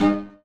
sci-fi_code_fail_13.wav